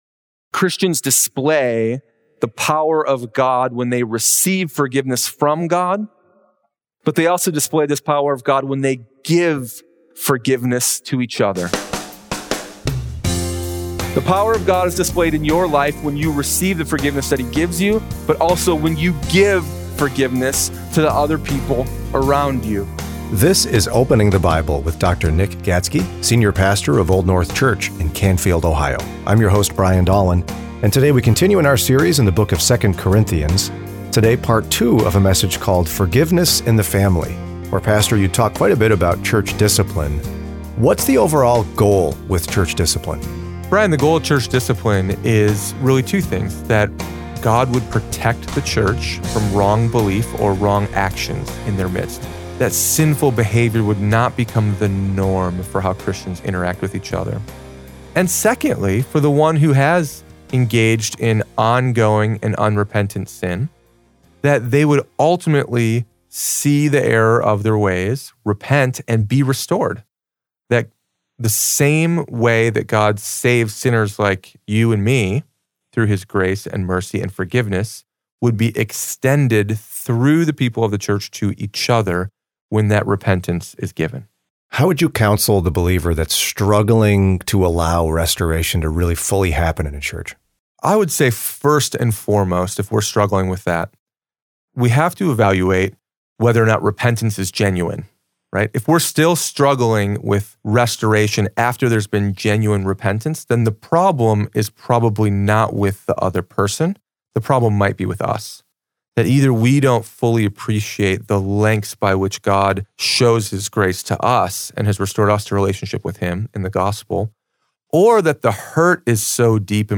Sermon Library – Old North Church